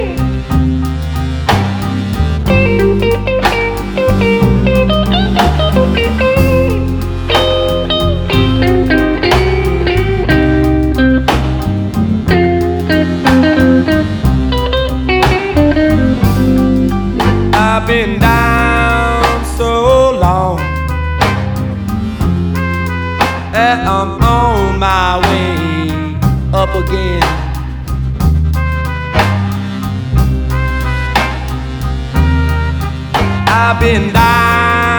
Жанр: Кантри